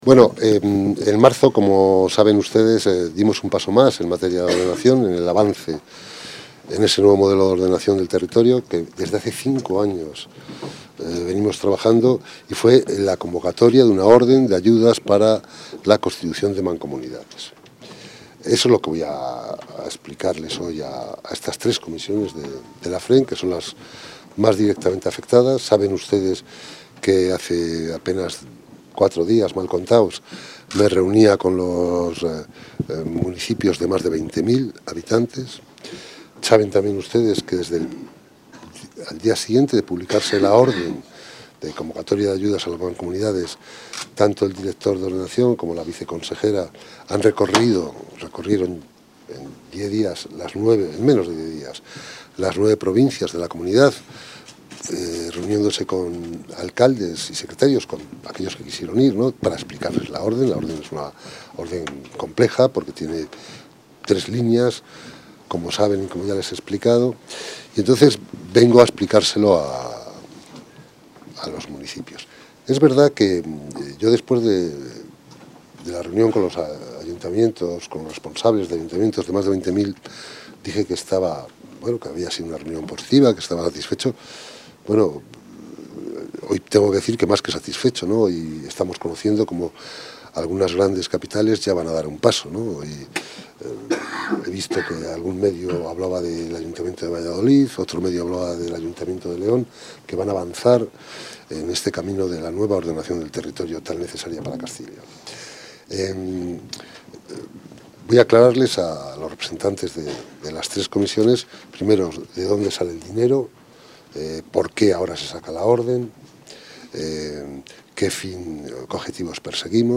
Material audiovisual de la comparecencia del vicepresidente de la Junta en la FRMP para informar sobre la convocatoria de ayudas para el fomento de las mancomunidades | Comunicación | Junta de Castilla y León